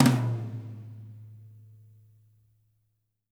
-TOM 2O   -L.wav